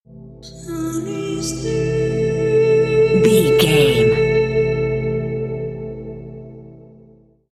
Aeolian/Minor
synthesiser
ominous
haunting